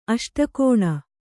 ♪ aṣtakōṇa